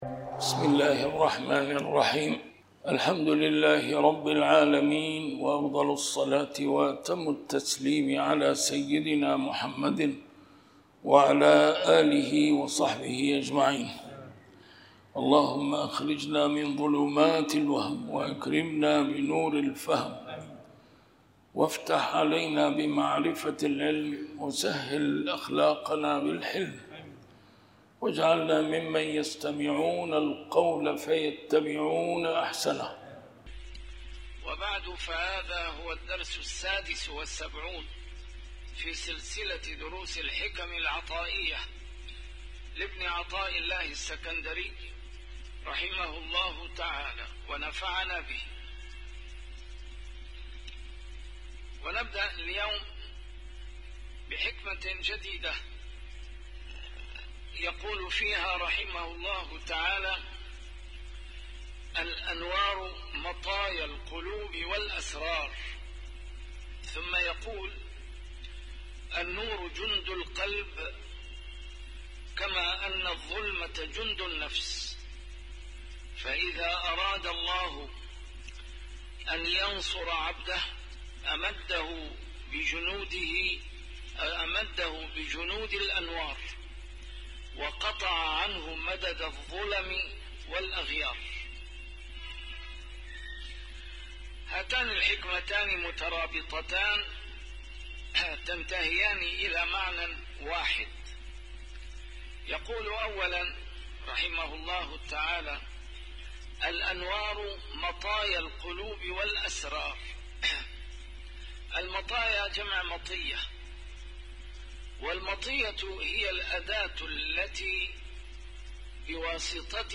A MARTYR SCHOLAR: IMAM MUHAMMAD SAEED RAMADAN AL-BOUTI - الدروس العلمية - شرح الحكم العطائية - الدرس رقم 76 شرح الحكمة 55